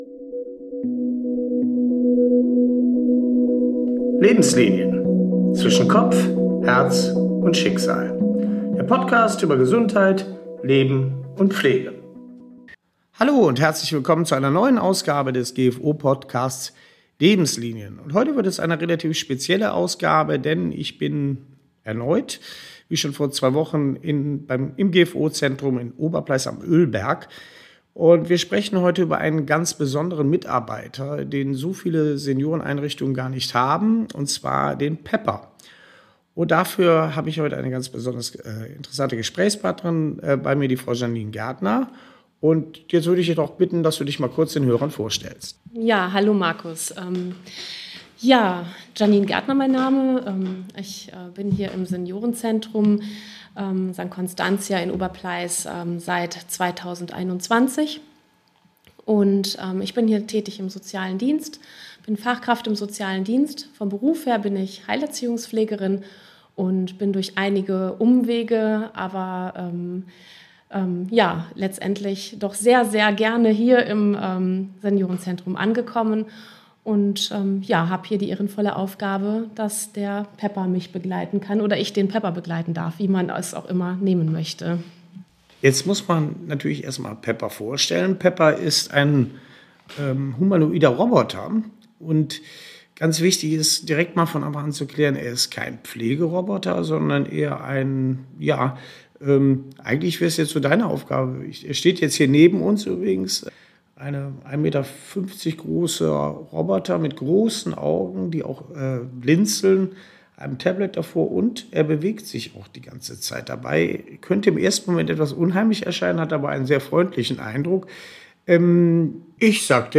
Zwischen Entertainer und Teammitglied: Roboter in der Pflege ~ Lebenslinien - Zwischen Kopf, Herz und Schicksal – der Podcast zu Gesundheit, Leben und Pflege Podcast